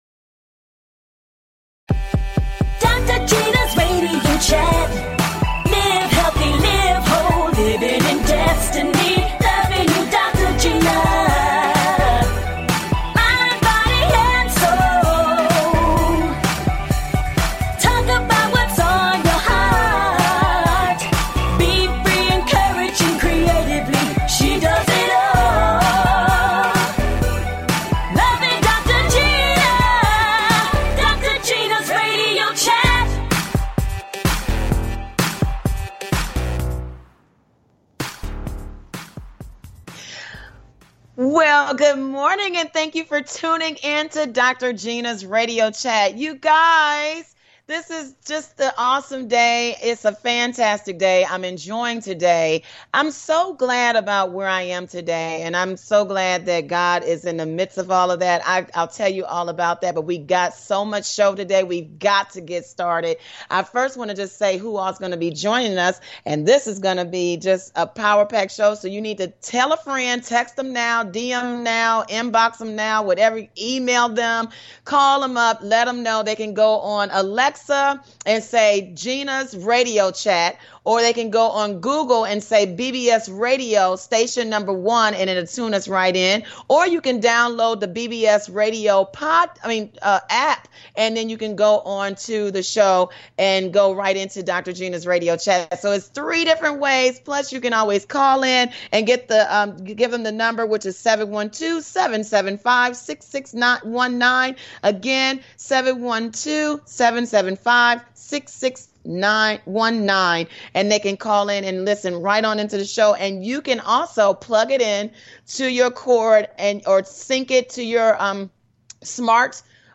Talk Show
And full of laughter!